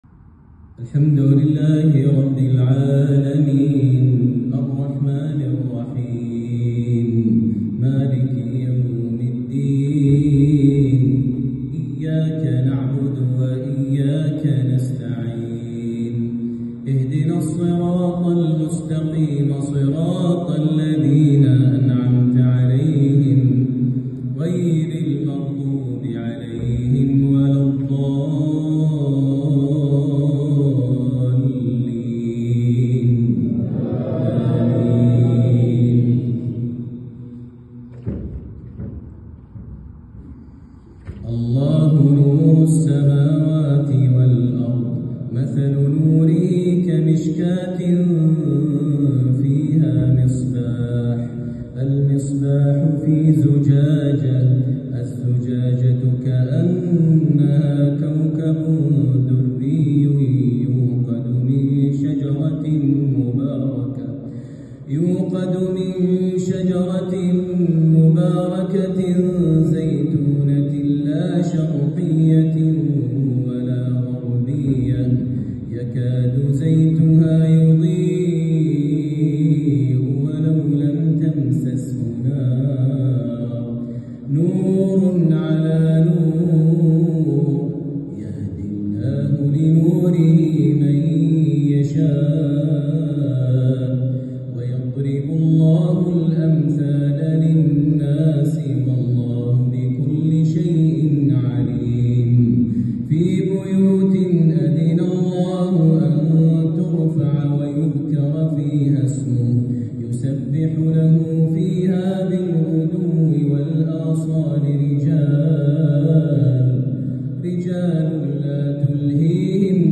صلاة العشاء للشيخ ماهر المعيقلي في الرياض _ جامع حصة الفارس | الإثنين ١٤ شعبان ١٤٤٧ هـ